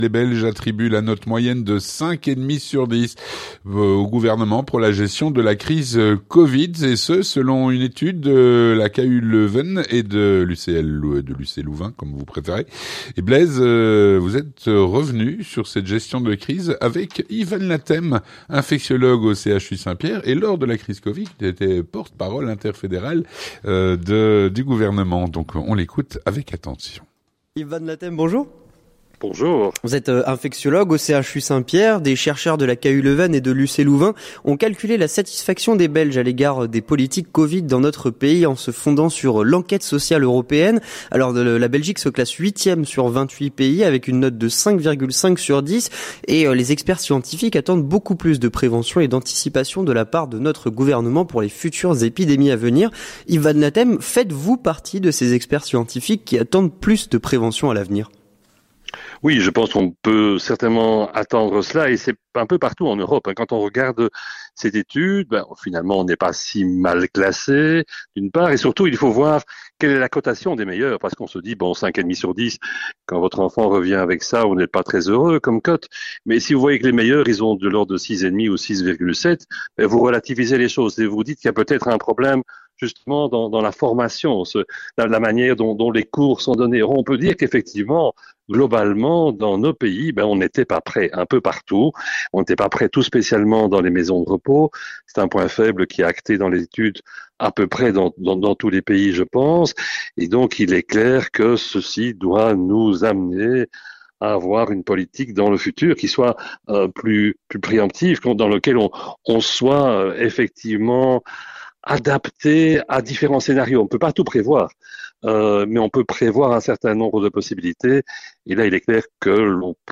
On revient sur cette période avec Yves Van Laethem, infectiologue au CHU Saint-Pierre